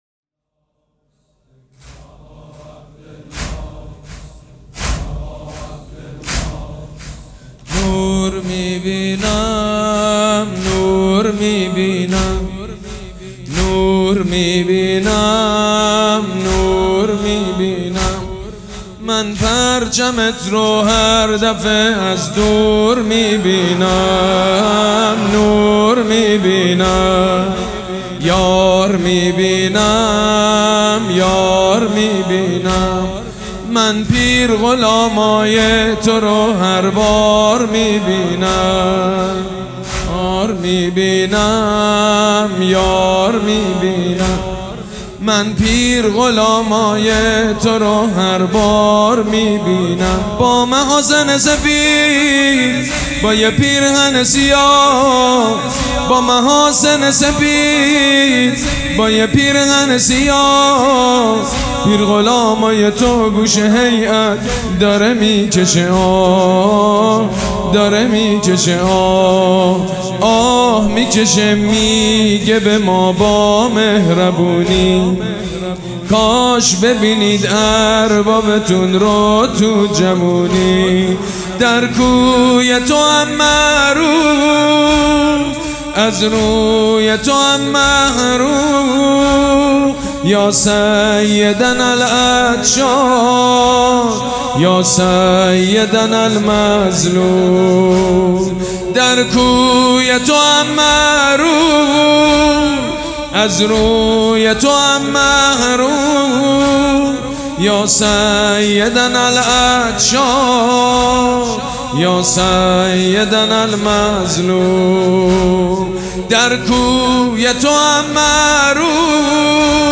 مداح
حاج سید مجید بنی فاطمه
مراسم عزاداری شب اول